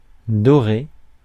Ääntäminen
UK : IPA : /ˈɡəʊl.dən/ US : IPA : /ˈɡoʊl.dən/